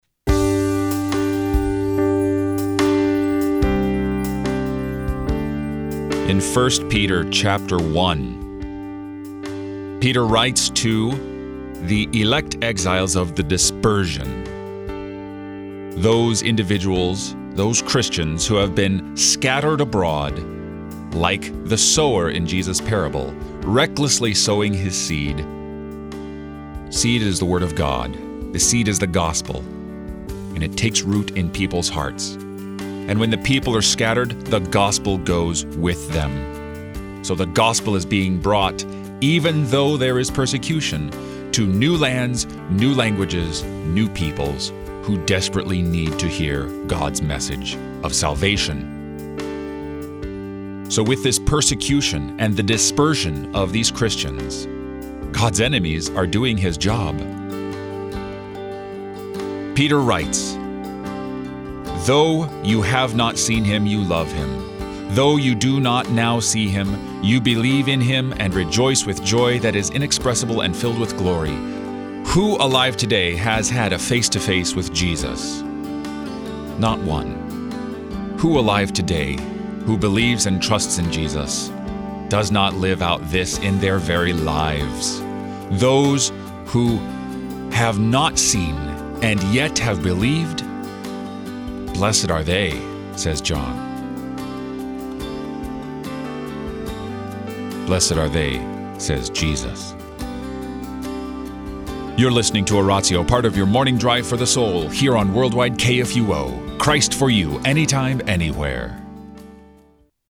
who shares scripture, hymns, prayers, and texts for the day, and also gives a short meditation on the day’s scripture lessons.